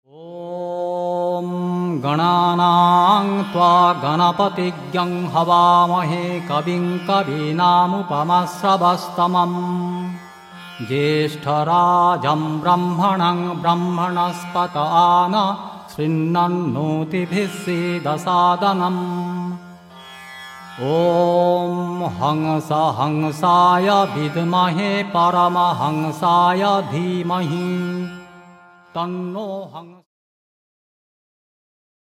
(Vedic mantras in chant and song)